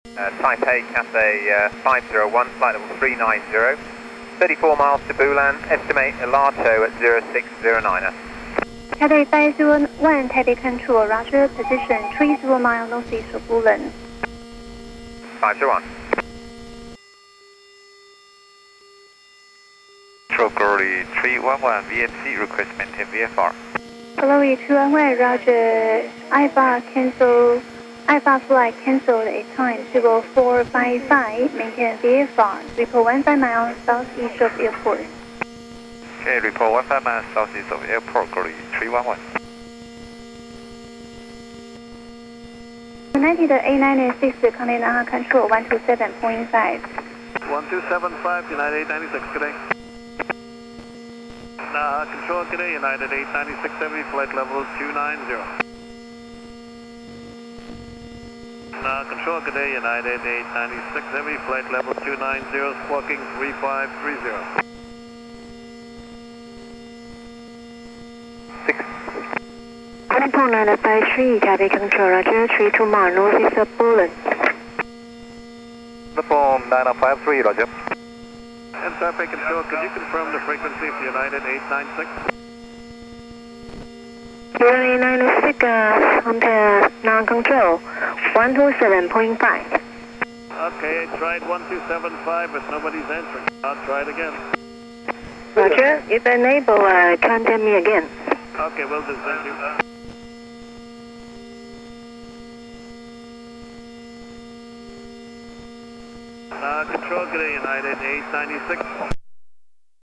Once enroute, I decided to record some of the air traffic control communications near mainland China.
3. With a new sound file open, I hit record, selecting "22k Hz mono" as my recording format -- ATC transmissions are over VHF frequencies similar to those you use to listen to AM radio, so recording in stereo is a waste of bandwidth.
I returned to the file later to double check the input level and found that it was surprisingly good -- I didn't have to tweak anything to get a smooth, normalized audio stream.
hk-atc.mp3